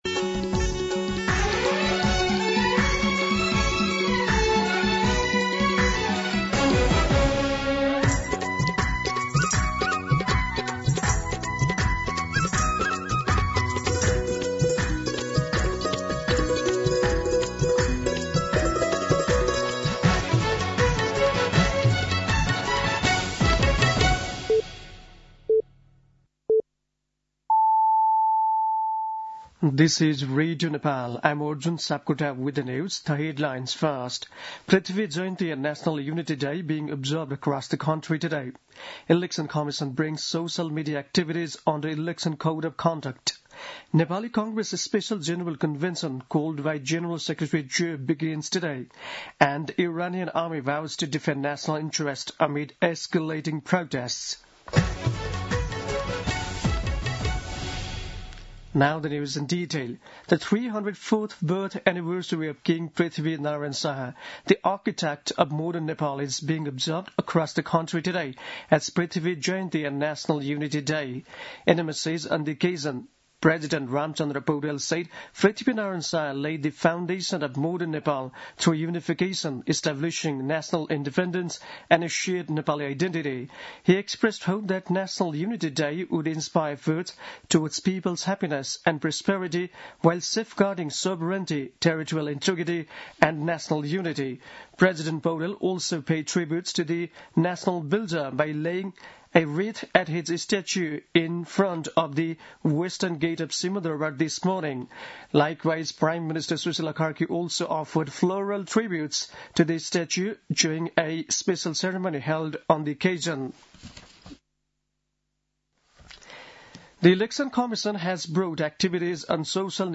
दिउँसो २ बजेको अङ्ग्रेजी समाचार : २७ पुष , २०८२